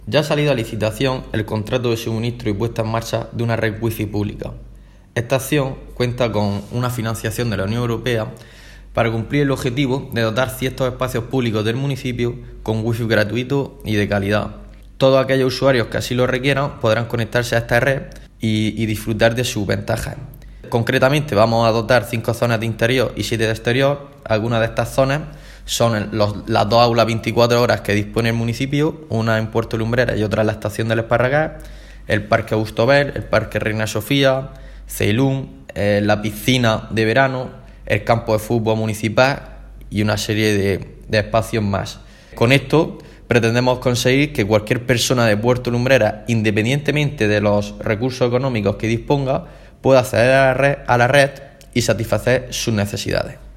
José-Manuel-Sánchez-concejal-de-Nuevas-Tecnologías.mp3